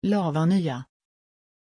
Aussprache von Lavanya
pronunciation-lavanya-sv.mp3